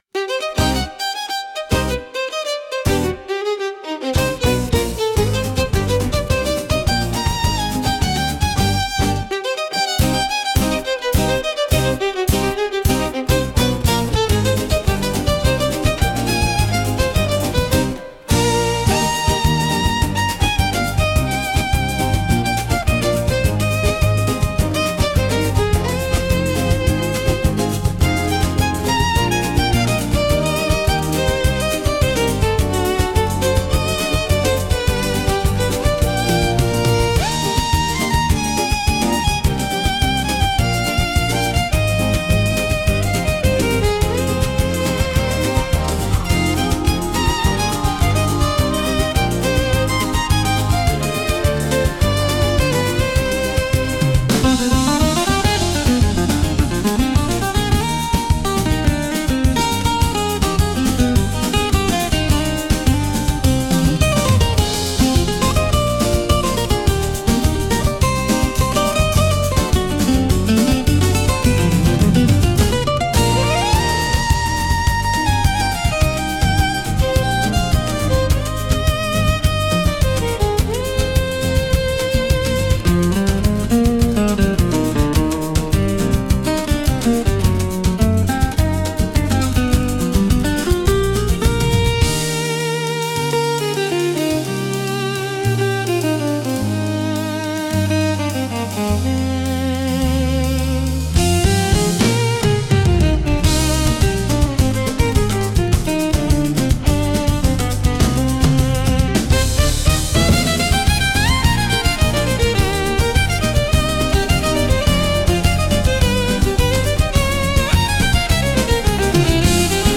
música E arranjo: IA) INSTRUMENTAL 4